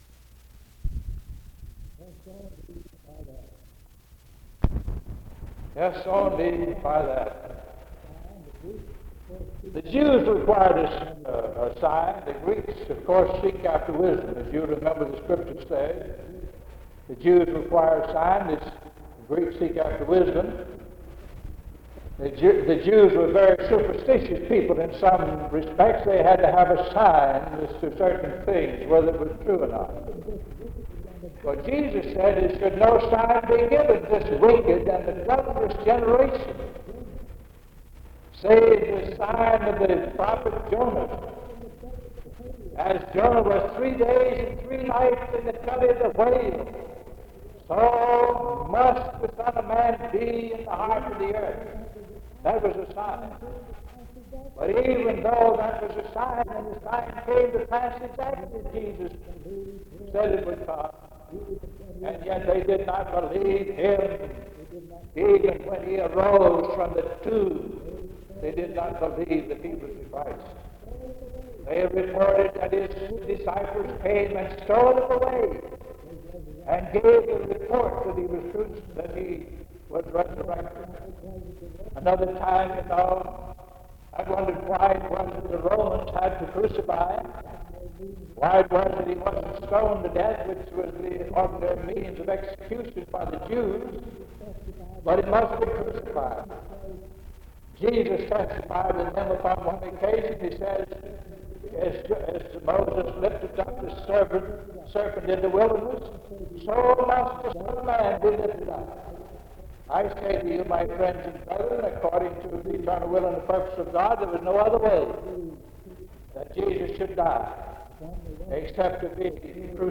Acts 2:22-28, (Very poor quality recording)